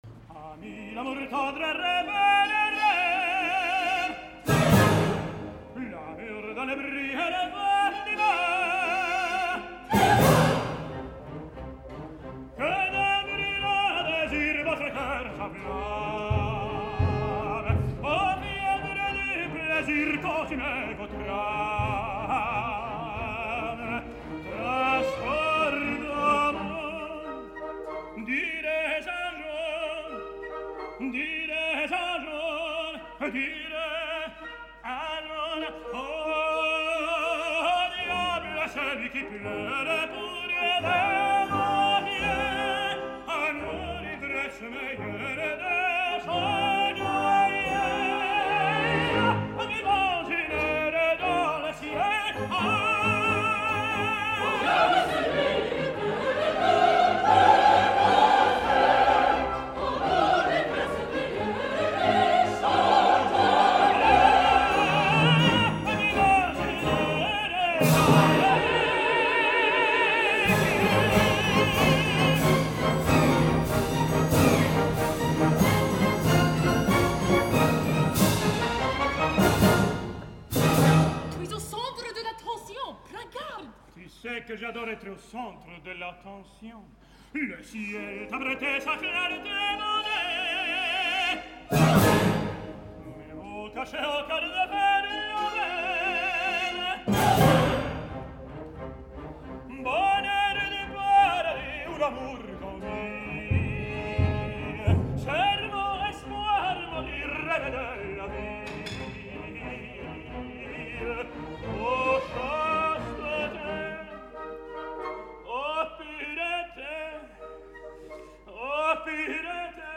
En el tercer acte, molt compromès pel tenor, Hoffmann inicia amb els couplets bachiques